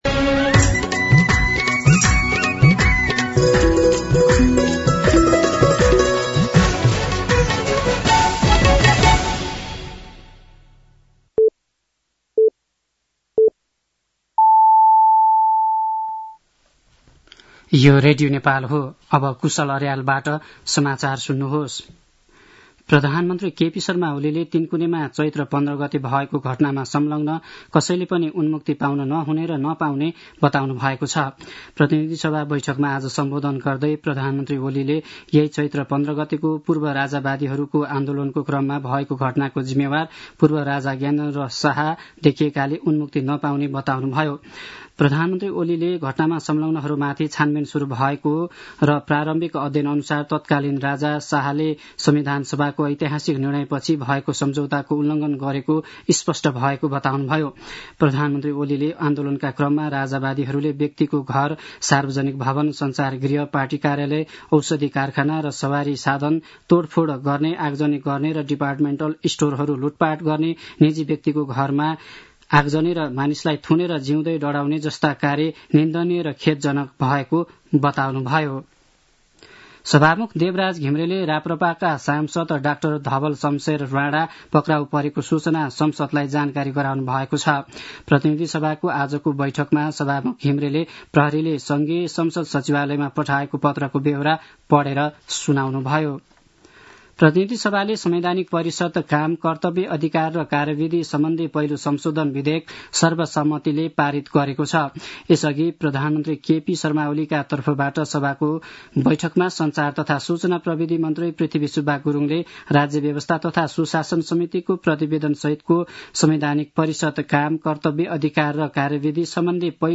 दिउँसो ४ बजेको नेपाली समाचार : १८ चैत , २०८१
4-pm-news-3.mp3